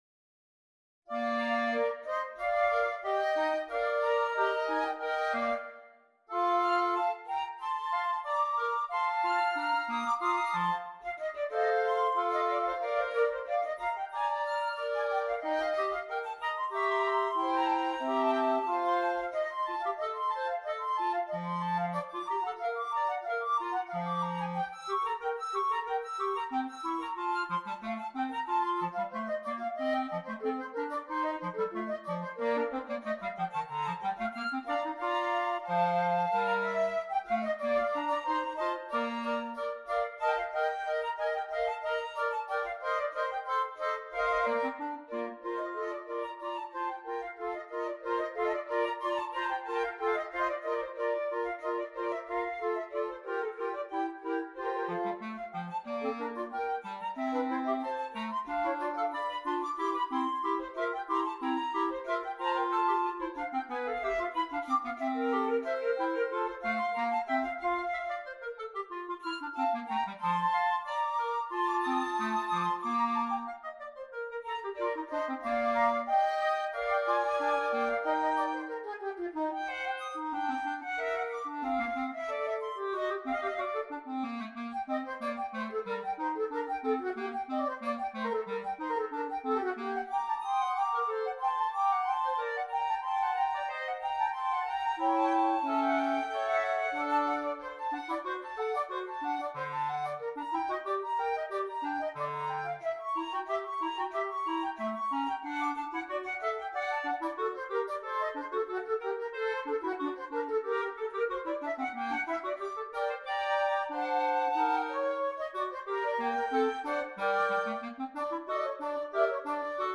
2 Flutes, 2 Clarinets